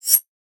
edm-perc-29.wav